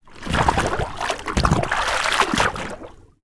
Water_43.wav